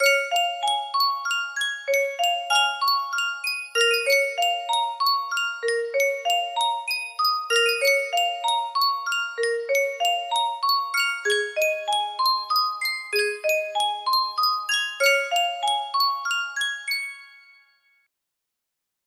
BPM 128